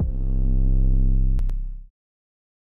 808s
808 (Sloppy Toppy).wav